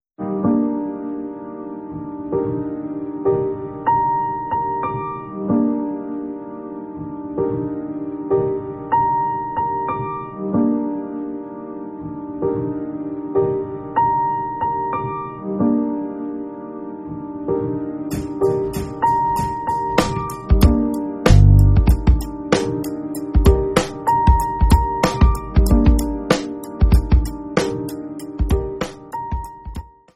naturally expressive and atmospheric
Electronic
Instrumental
Jamband
Psychedelic